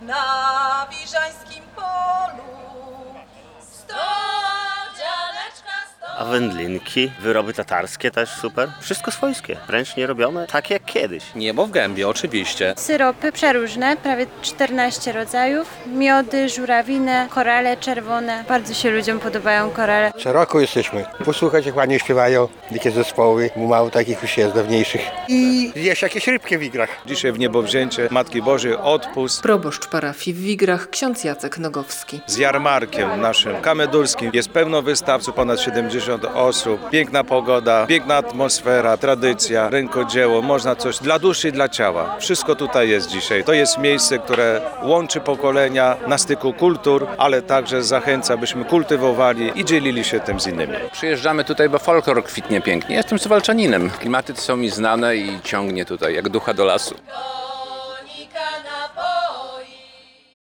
W ogrodach klasztornych odbywa się bowiem Jarmark Kamedulski "Na styku kultur".
Bardzo się ludziom podobają te korale - zachwalali swoje wyroby wystawcy.